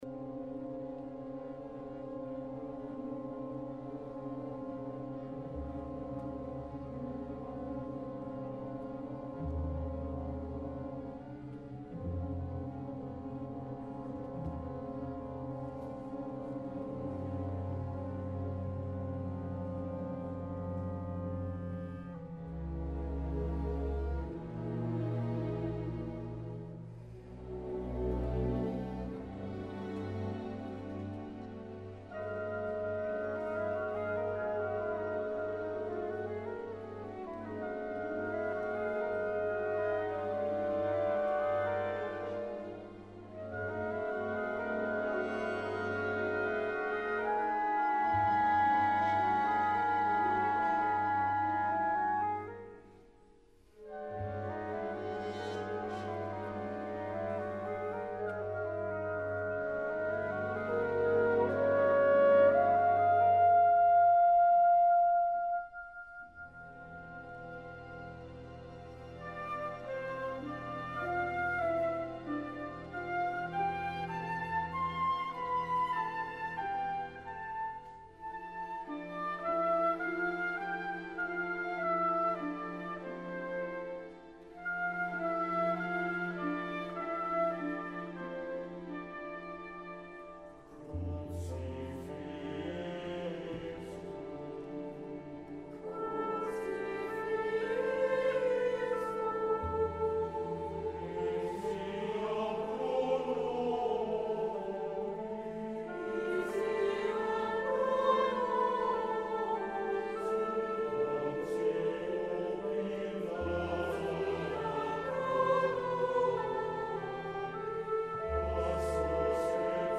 Sopran Solo
Chor (SATB)